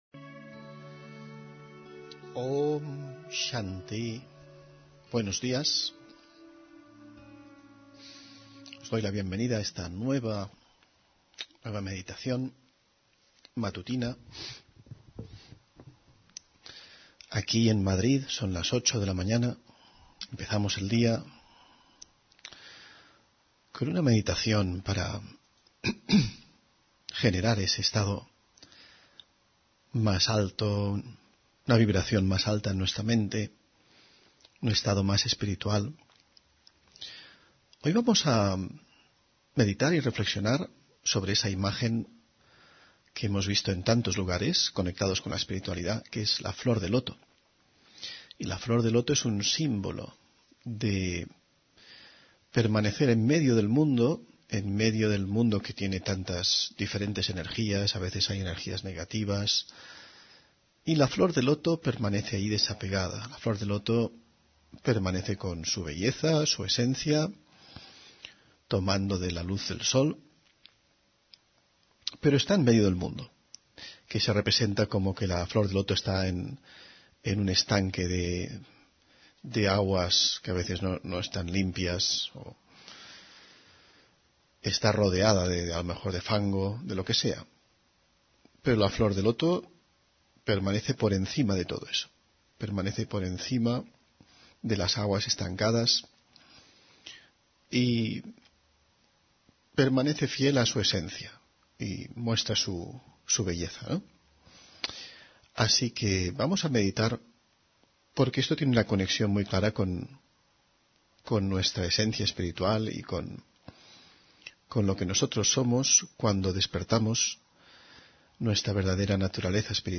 Meditación de la mañana: Mantenerte en lo esencial